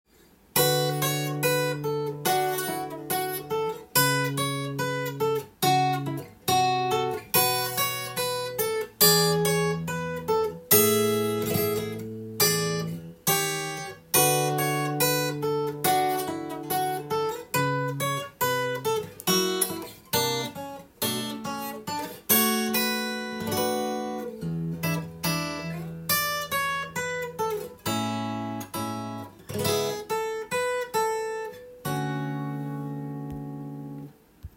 サビの部分をアコースティックギターで弾けるよう譜面にしました。
譜面通りギターで弾いてみました